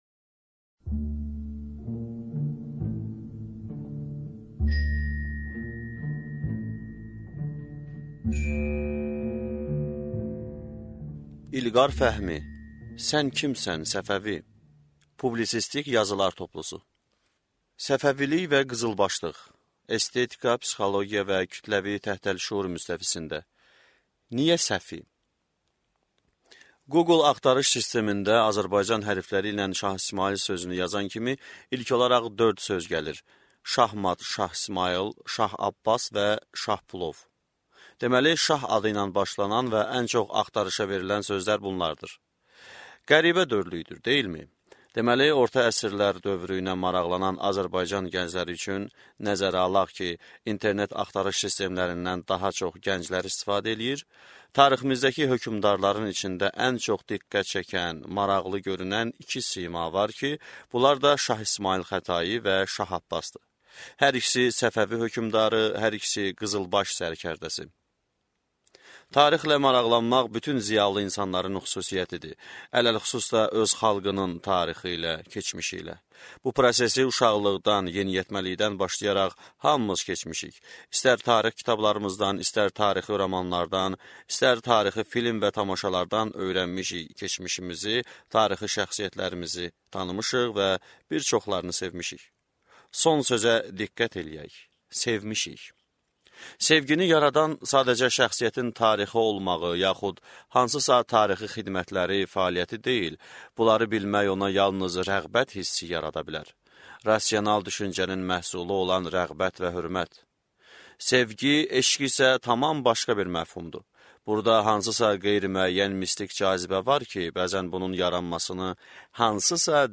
Аудиокнига Sən kimsən, Səfəvi | Библиотека аудиокниг